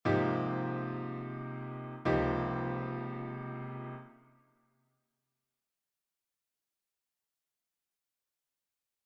Improvisation Piano Jazz
Ce voicing contient les même notes qu’un AbMaj7 ou DbMaj7#11, d’où d’autres idées de voicings ou de substitution pour ces accords :